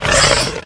Divergent / mods / Soundscape Overhaul / gamedata / sounds / monsters / chimera / attack_1.ogg
attack_1.ogg